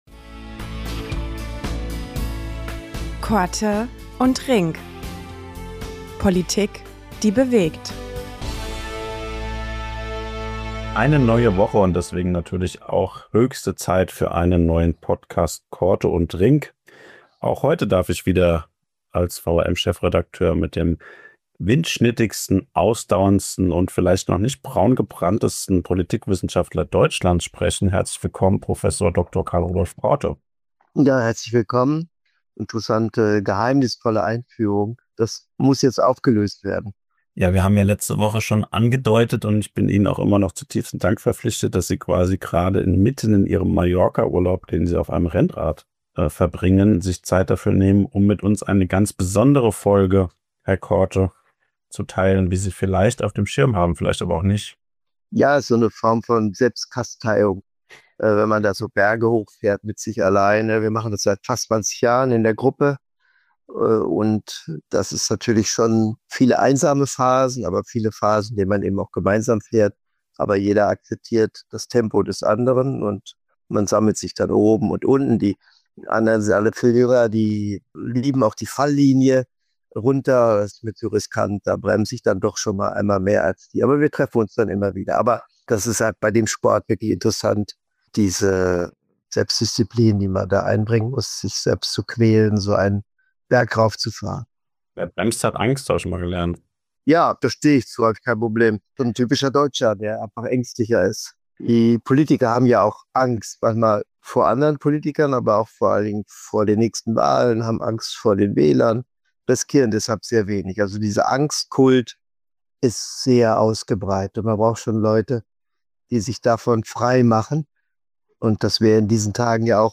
Zur Jubiläumsfolge meldet sich Politikwissenschaftler Prof. Dr. Karl-Rudolf Korte direkt aus dem Rennrad-Trainingslager auf Mallorca – mit aktuellen Beobachtungen zur politischen Lage.